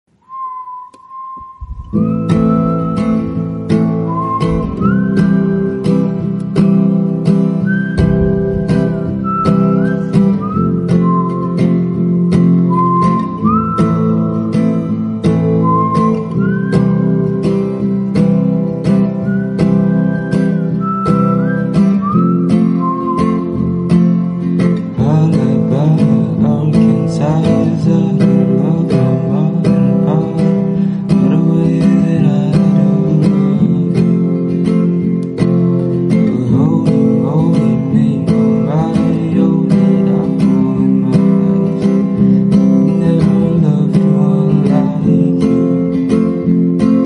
Take a pause and listen to the sound of serenity!!💕💕